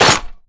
gun_chamber_jammed_01.wav